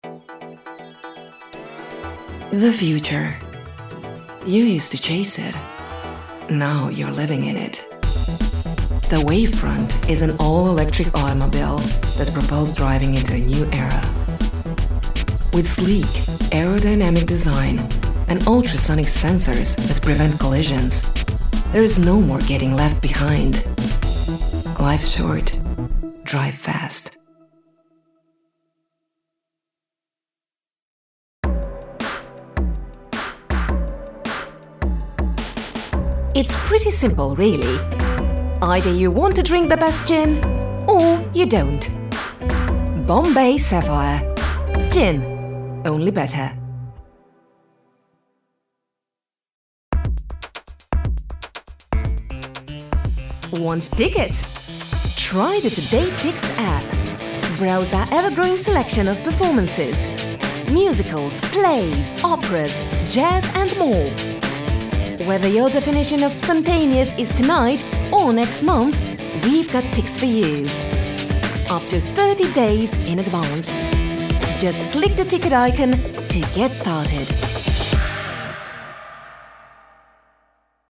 Njezin glas karakterizira uglađena, senzualna i umirujuća boja te svestrani vokalni stil koji se kreće u rasponu od toplog, senzualnog do razigranog, autoritativnog, korporativnog, energičnog.
Commercial reel
Commercial_reel.mp3